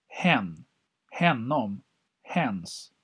Ääntäminen
IPA: /hɛn/